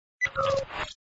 incoming trans screen up.wav